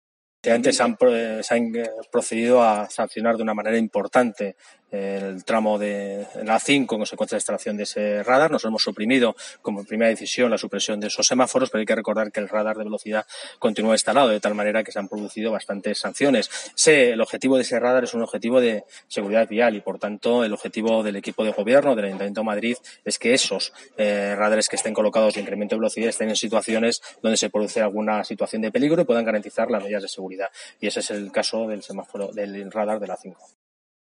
Nueva ventana:Declaraciones de Borja Carabante sobre radares y soterramiento A5